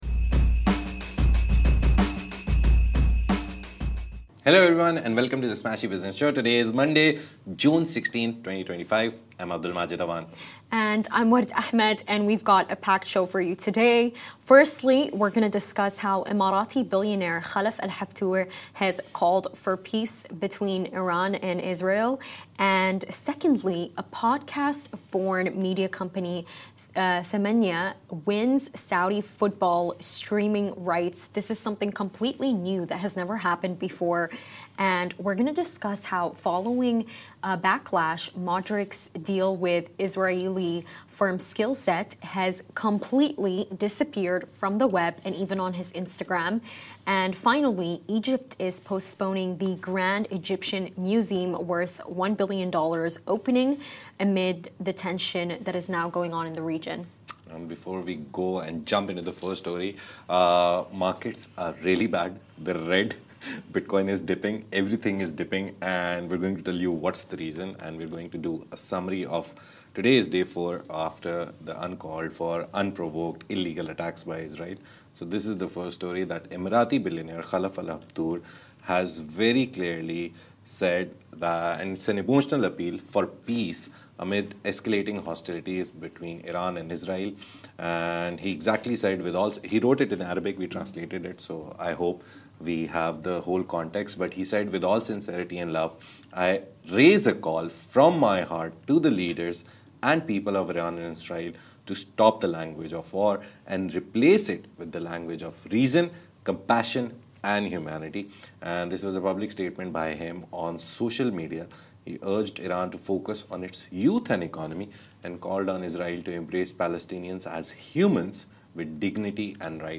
The Smashi Business Show is where Smashi interviews the business leaders who make a difference in this great city.